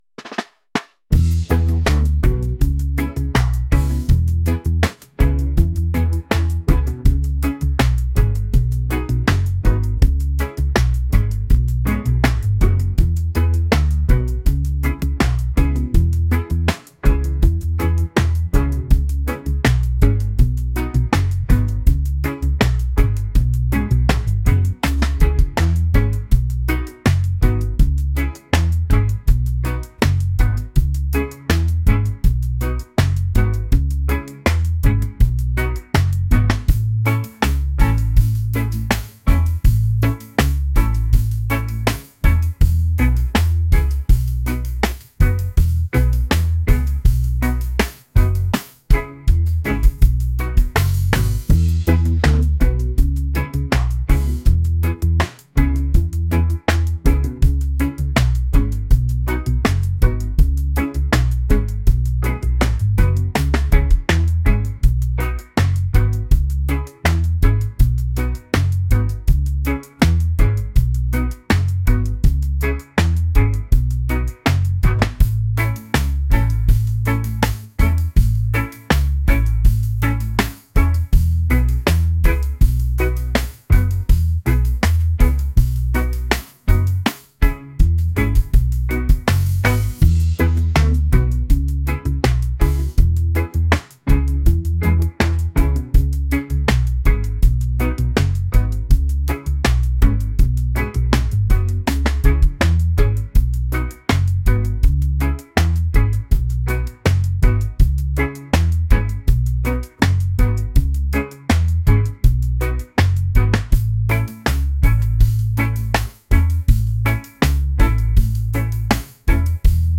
reggae | laid-back | groovy